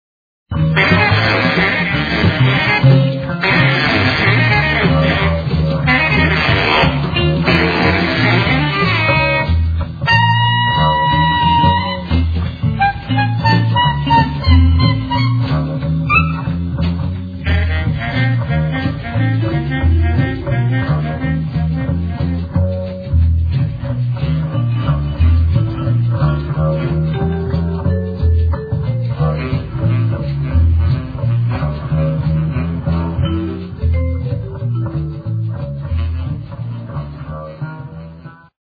freejazz
baritone saxophone
el. doublebass
drums
steel, el. guitar). Live from Pardubice [2002].